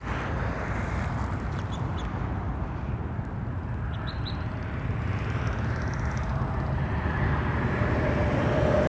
Tropical Kingbird
Orleans